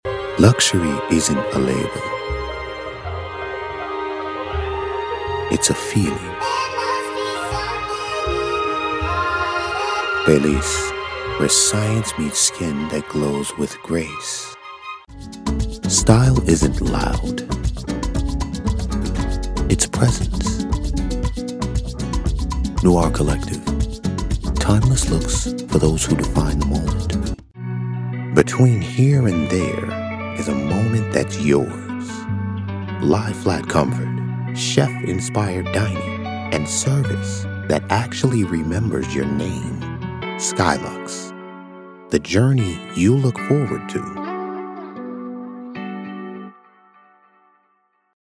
Style in every syllable. Depth in tone.
Commercial Demo